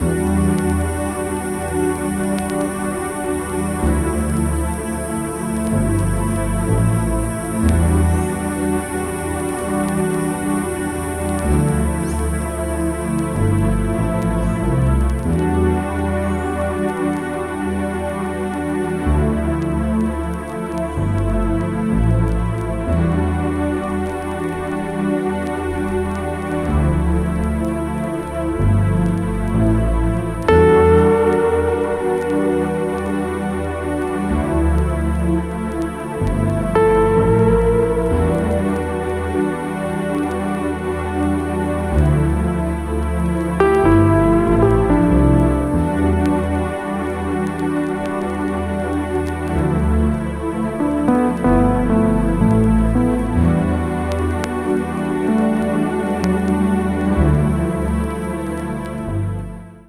90s AMBIENT JAZZFUNK / FUSION 異世界 詳細を表示する